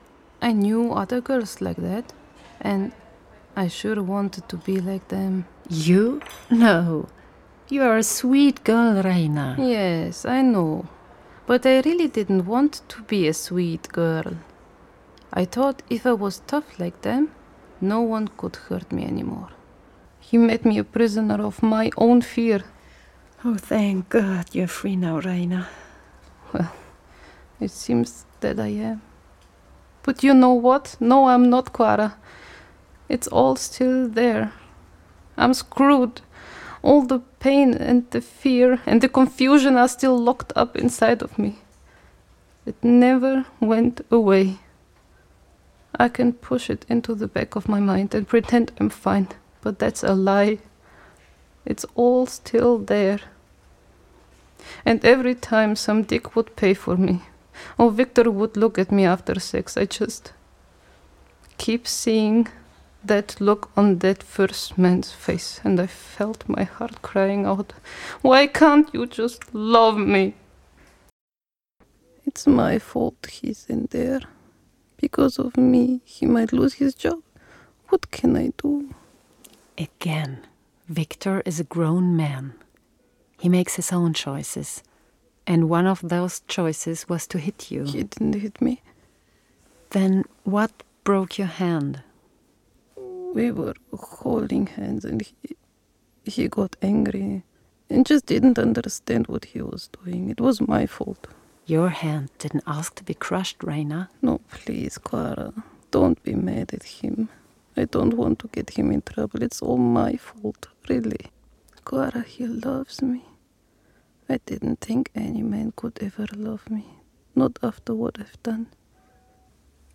Ник Кейв Немски: Български: Реклама Питки Дамян Дамянов Науки Английски: Аудио Драма Making of Hidden Treasures Project Trans World Radio, released 2016 Френски: